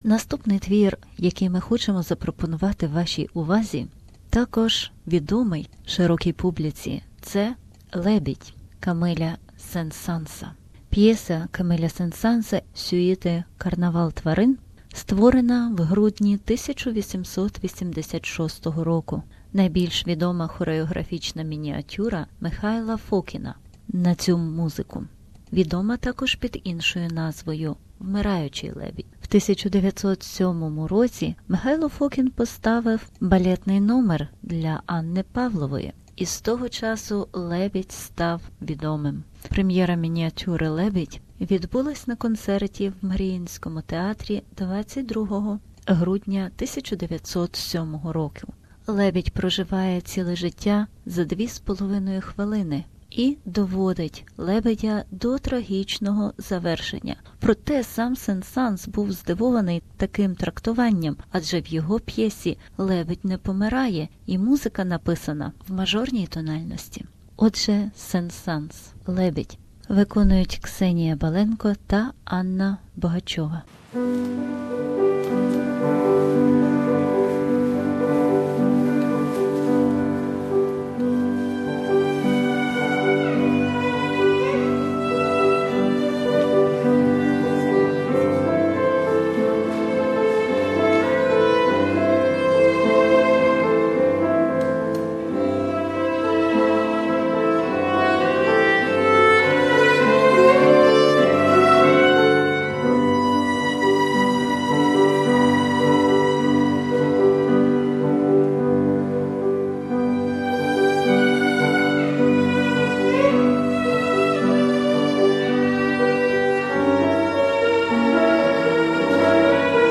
Diary ( p.2 ) from Ukrainian Cultural week in Melbourne The Swan p2